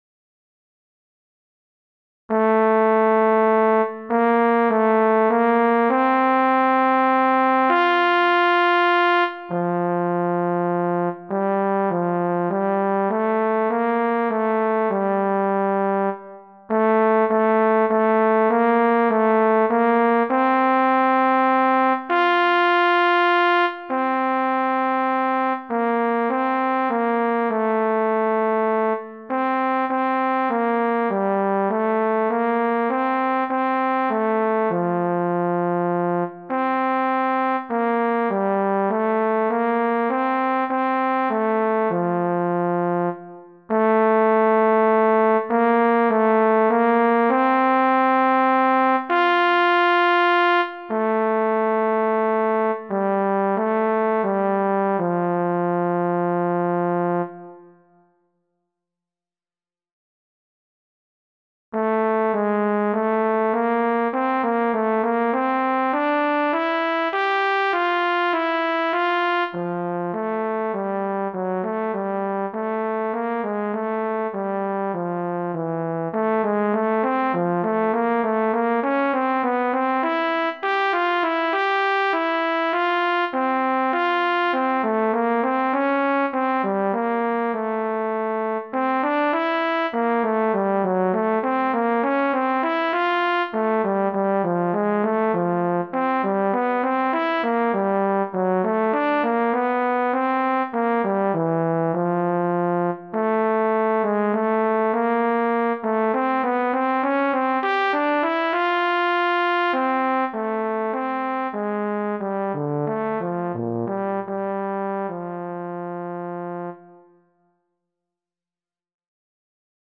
Pour saxhorn alto ou autre cuivre solo, sans accompagnement.